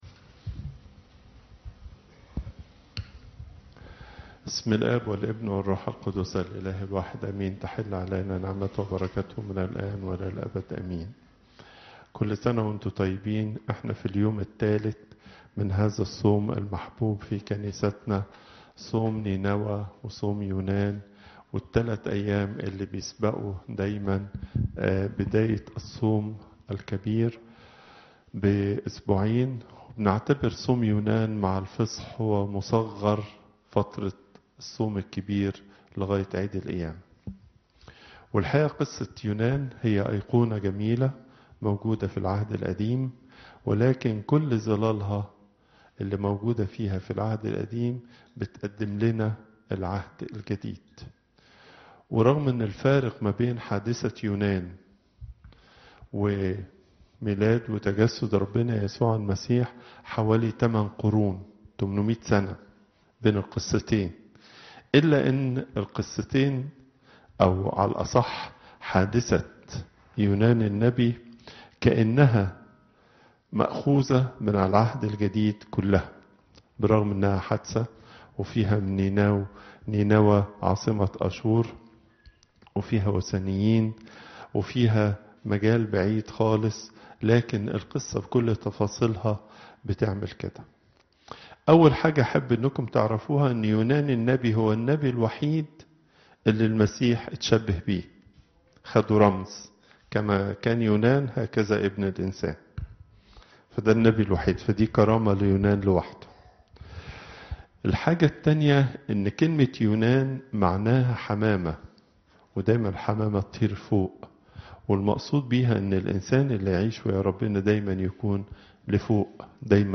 Popup Player Download Audio Pope Twadros II Wednesday, 31 January 2018 15:42 Pope Tawdroes II Weekly Lecture Hits: 758